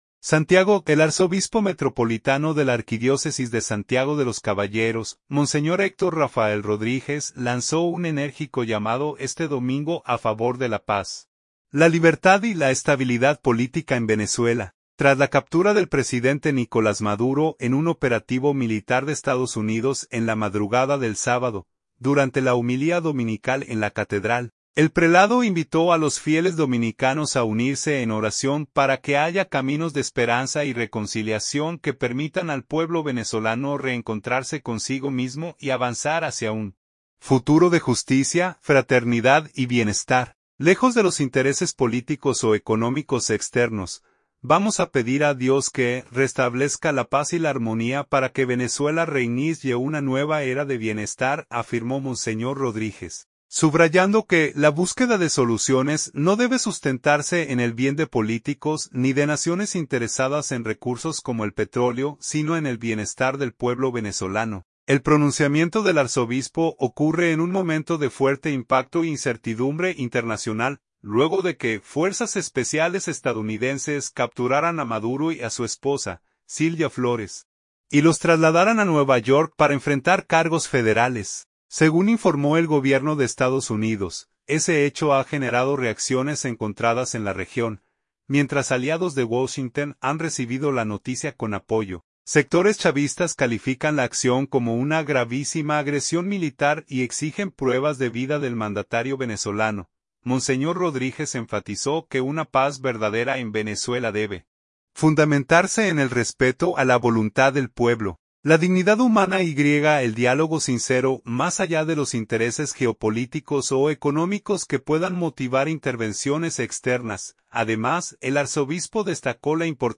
Durante la homilía dominical en la catedral, el prelado invitó a los fieles dominicanos a unirse en oración para que “haya caminos de esperanza y reconciliación” que permitan al pueblo venezolano reencontrarse consigo mismo y avanzar hacia “un futuro de justicia, fraternidad y bienestar”, lejos de los intereses políticos o económicos externos.